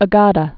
(ə-gädə)